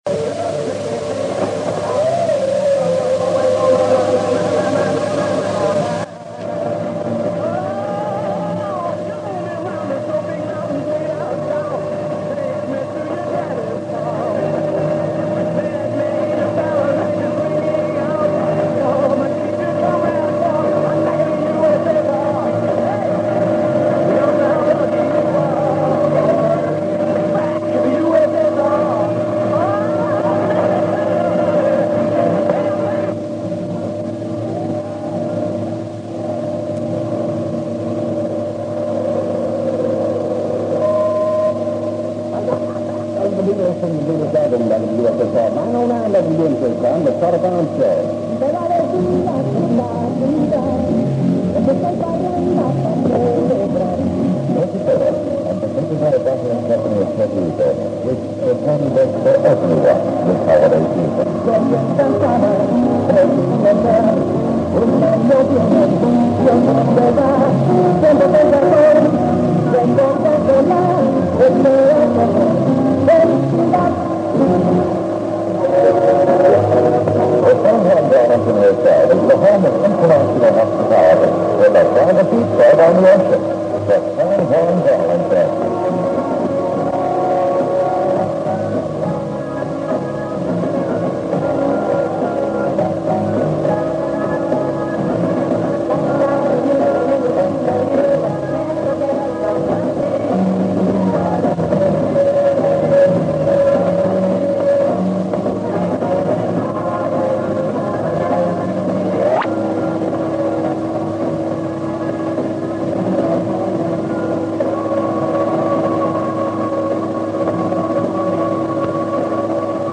DX-Memories from 1968:
With its distinctive sound and top modulation the station was frequently picked up in Scandinavia in the years to come.